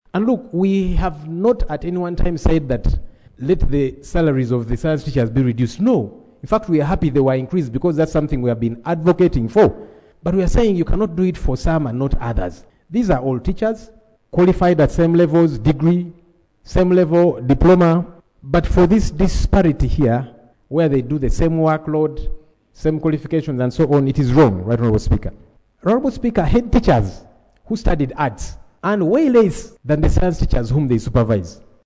He offered the advice during plenary on Tuesday, 24 June 2025, wherein he warned that arts and humanities teachers across the country had gone on strike, demanding pay equity and the matter needs to be addressed.
AUDIO: Hon. Joel Ssenyonyi